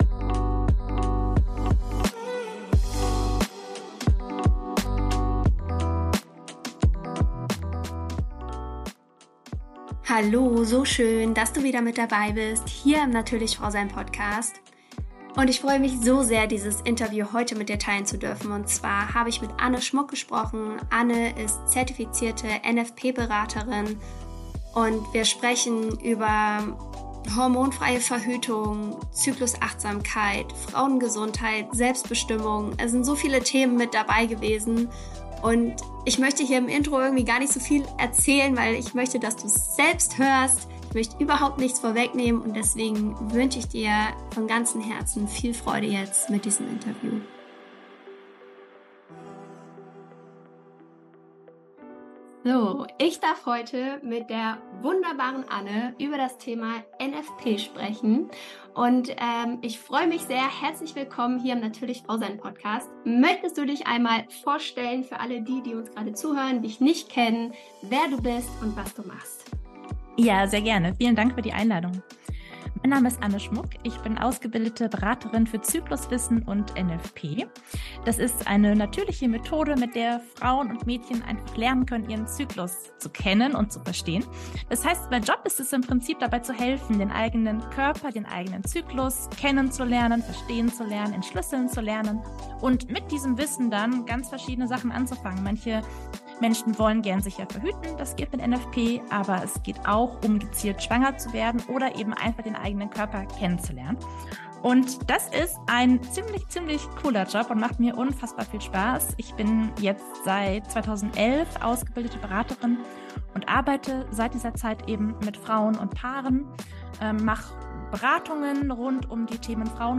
In dem Interview sprechen wir natürlich über die Methode, aber auch über die Vorteile und Auswirkungen dieses Wissens auf uns, den eigenen Körper, das Leben und die Partnerschaft.